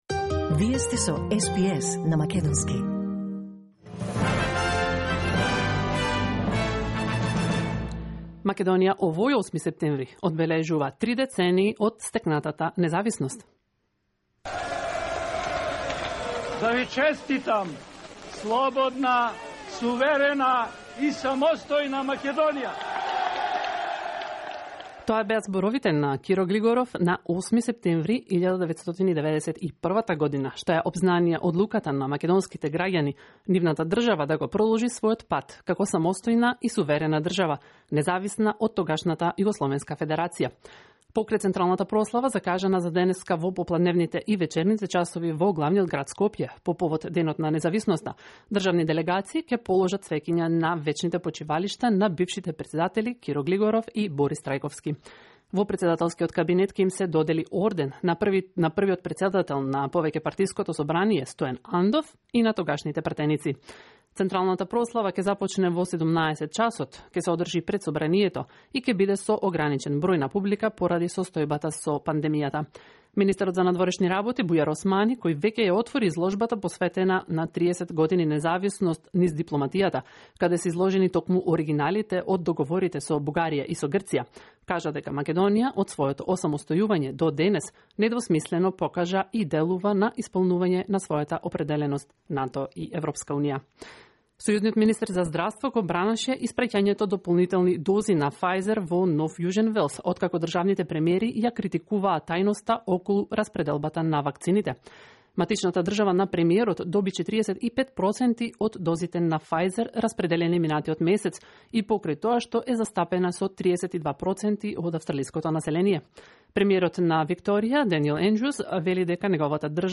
SBS News in Macedonian 8 September 2021